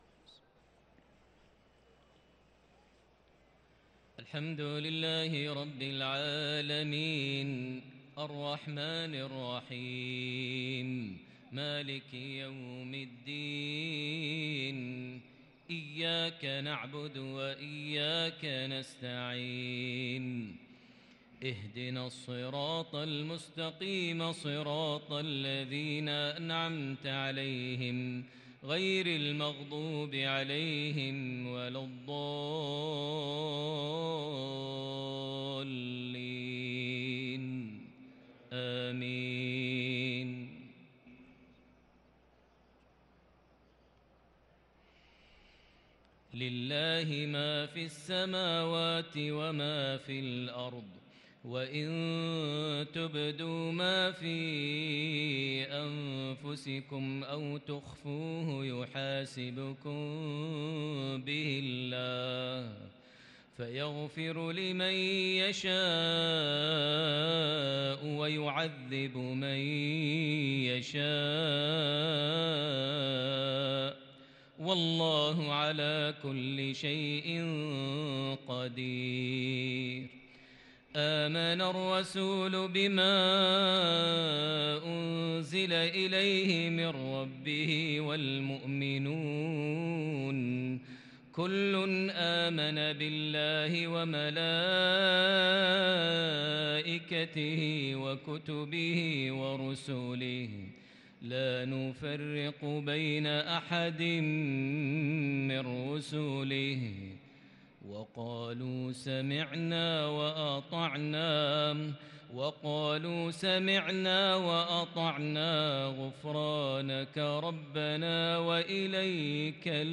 صلاة المغرب للقارئ ماهر المعيقلي 13 جمادي الأول 1444 هـ
تِلَاوَات الْحَرَمَيْن .